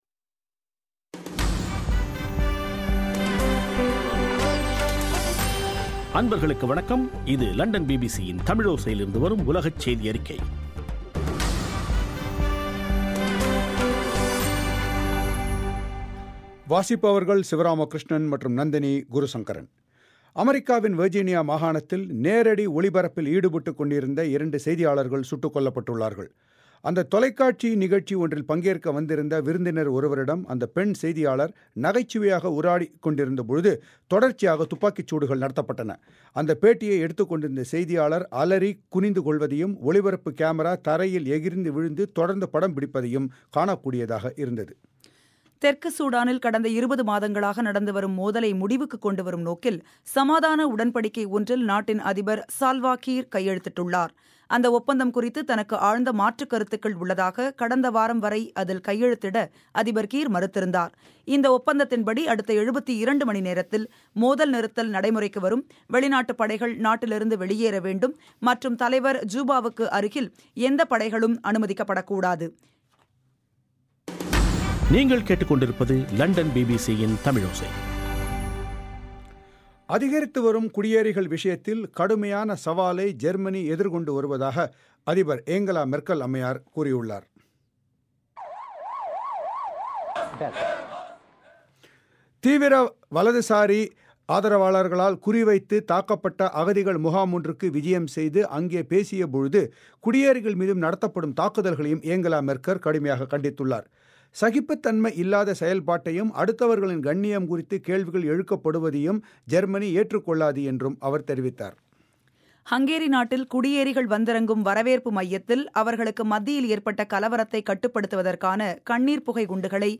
ஆகஸ்ட் 26, 2015 பிபிசி தமிழோசையின் உலகச் செய்திகள்